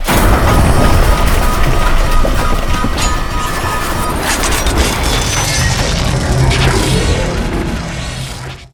fall.ogg